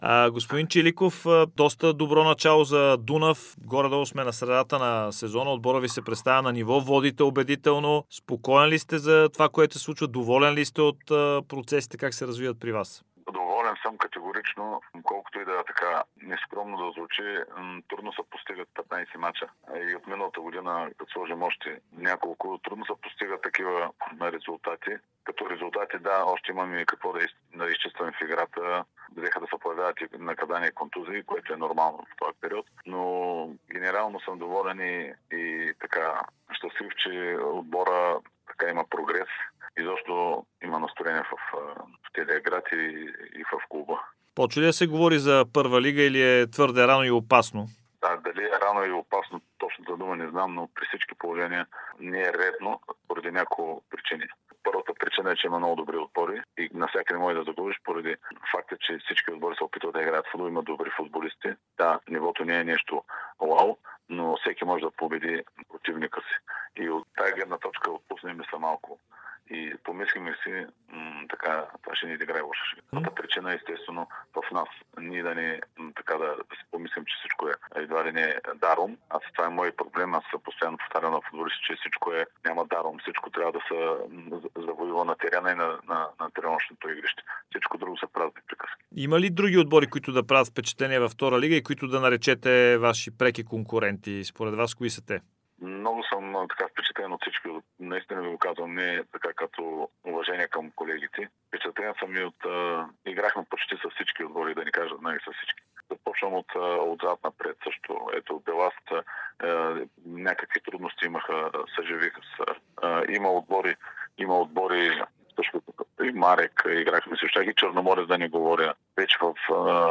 Старши треньорът на Дунав Георги Чиликов даде специално интервю пред Дарик радио и dsport, в което говори за представянето на отбора от Русе от началото на сезона във Втора лига, кризата в Лудогорец и нивото на Първа лига.